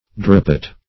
drapet - definition of drapet - synonyms, pronunciation, spelling from Free Dictionary Search Result for " drapet" : The Collaborative International Dictionary of English v.0.48: Drapet \Dra"pet\, n. [Dim. of drap.]